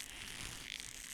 trim.wav